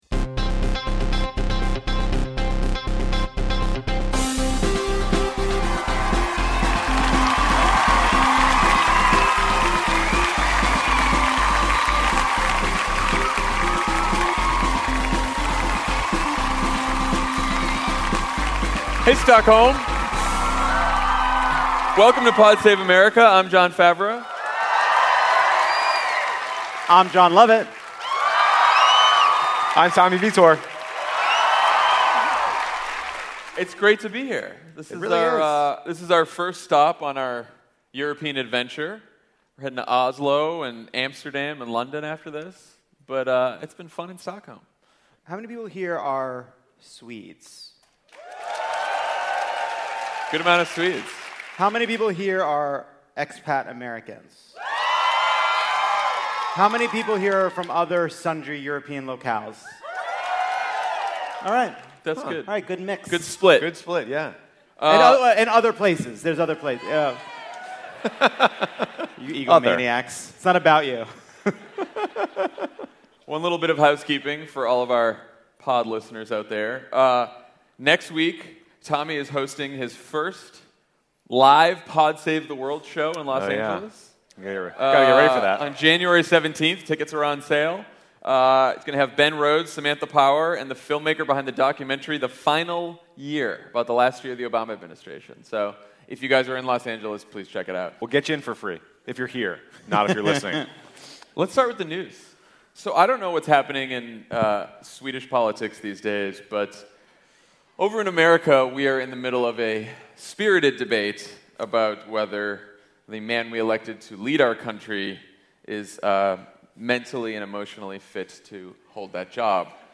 The President assures the country of his mental stability, Mueller hones in on obstruction while Republicans in Congress try to undermine him, and the Democrats plot their strategy to protect the DREAMers. Jon, Jon, and Tommy do the pod live from Stockholm, Sweden.